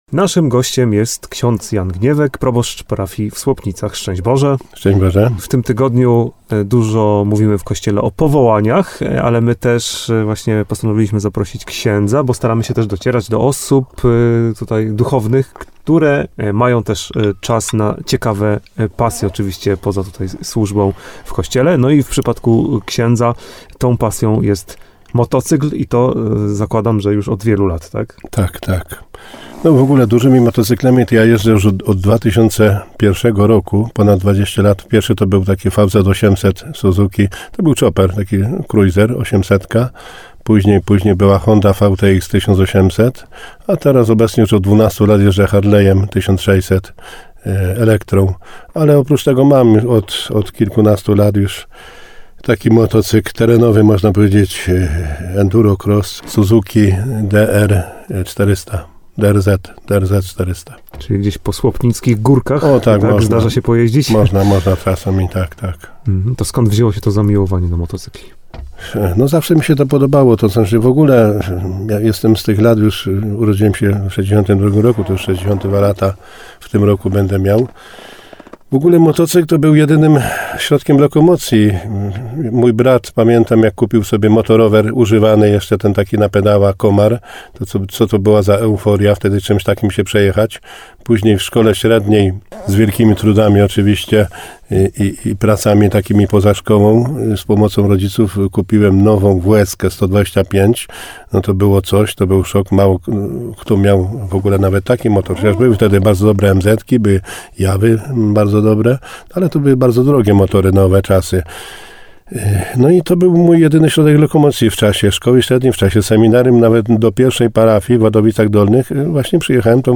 Motocykle i nie tylko [ROZMOWA]